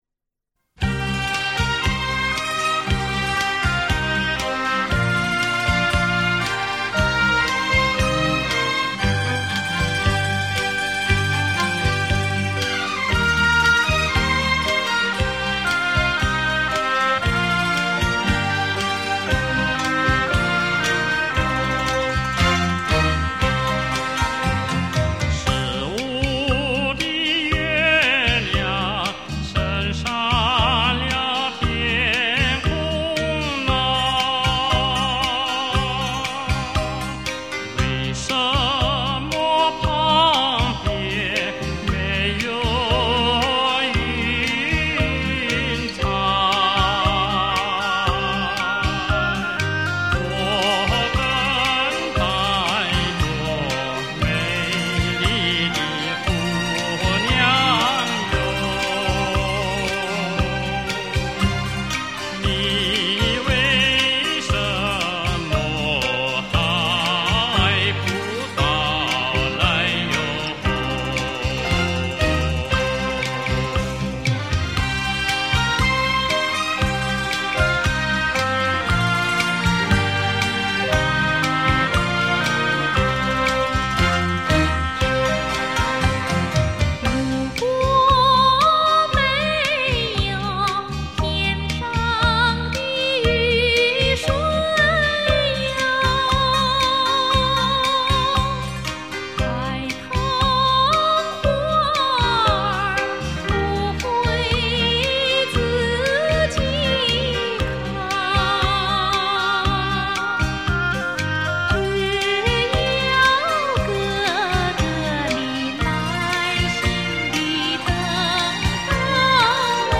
中国顶级民族歌唱家。